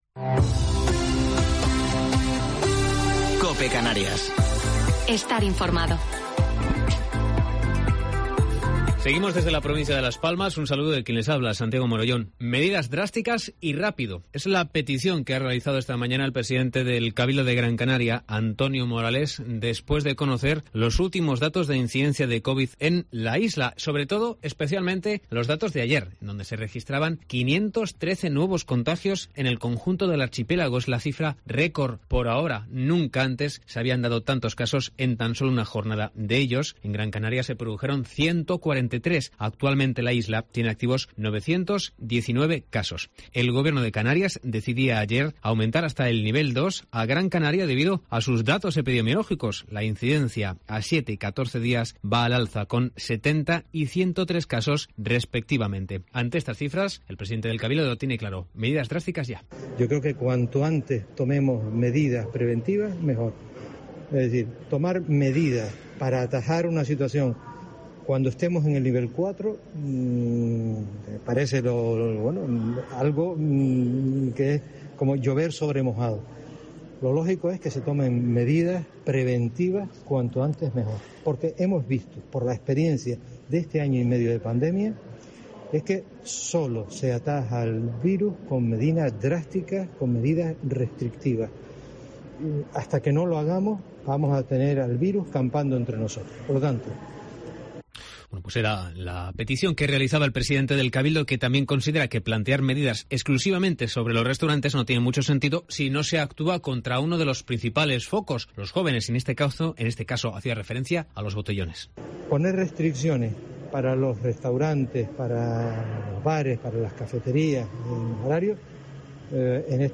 Informativo local 9 de Julio del 2021